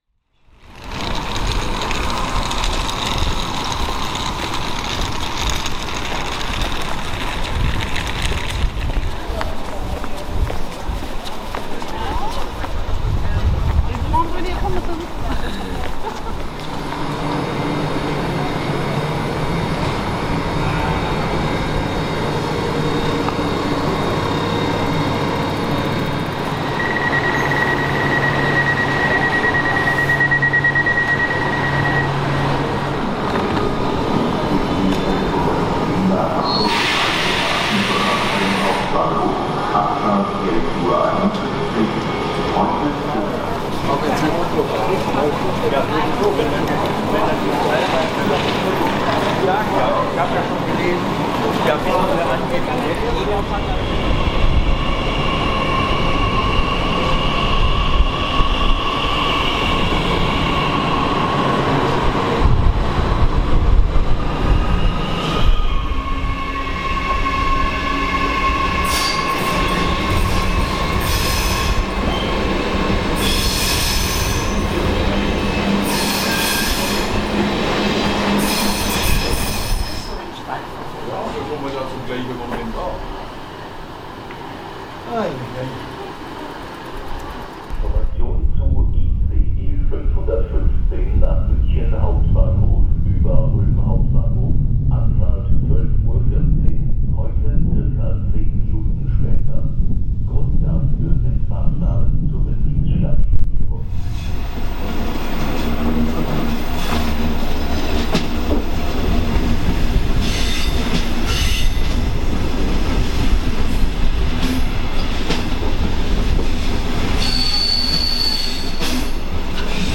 Hör mal hin! 3 Minuten Stuttgarter Bahnhofsatmosphäre